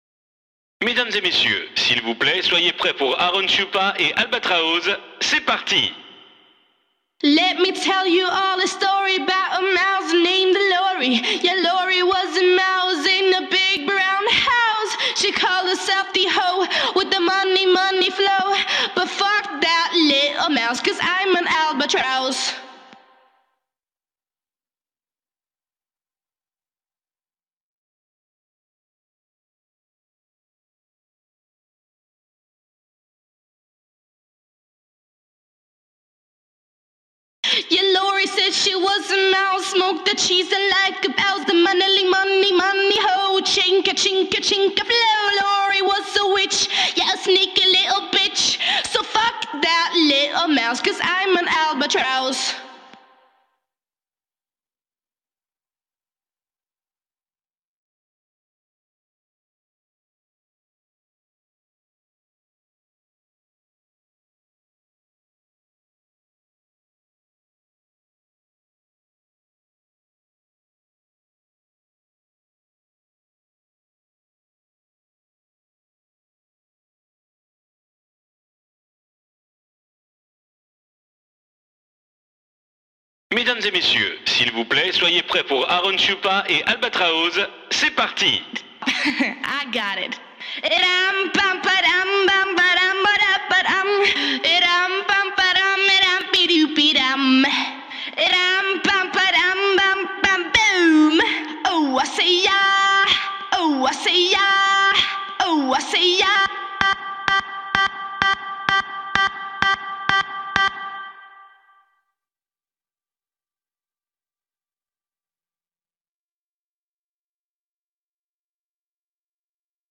Главная » Файлы » Акапеллы » Скачать Зарубежные акапеллы